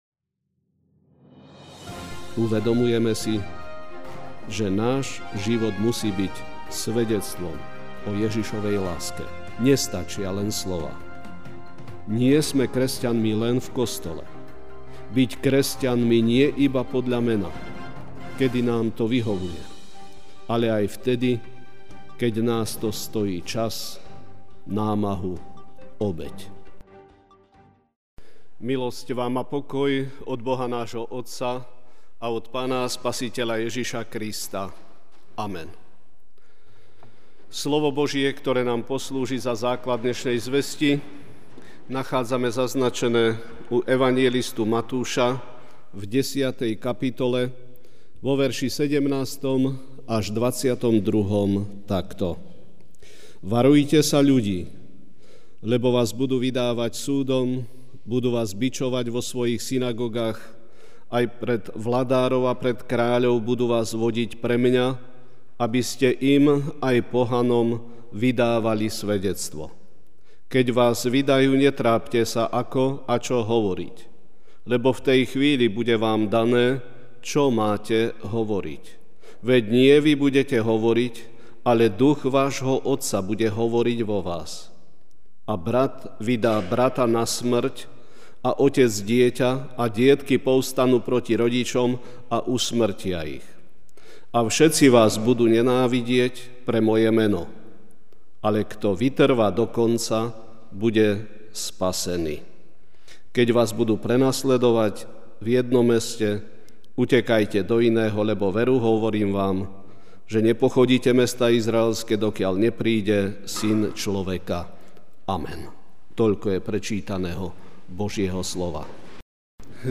Ranná kázeň: Svedectvo (Mt 10, 17-22)